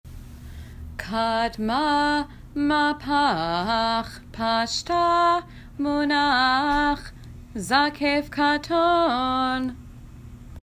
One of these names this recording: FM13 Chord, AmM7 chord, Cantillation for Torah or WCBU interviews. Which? Cantillation for Torah